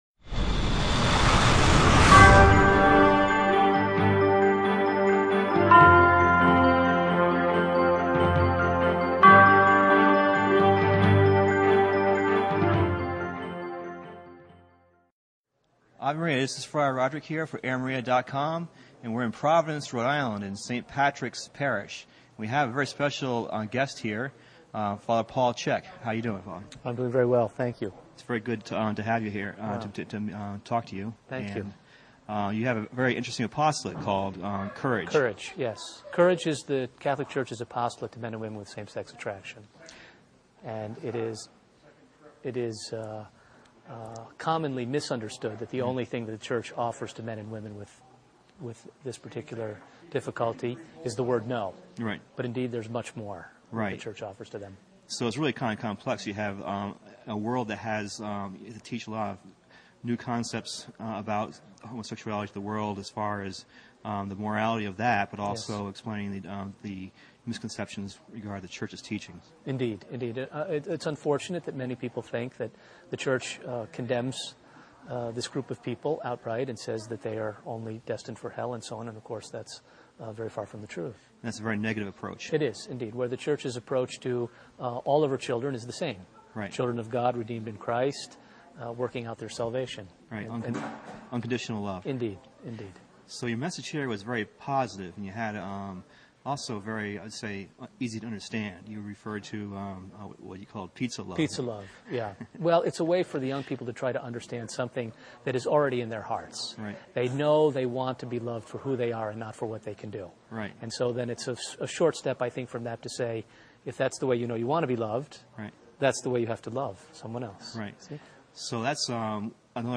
I will post the transcript of this interview on our local blog and a link to this video to reach a greater number of audience.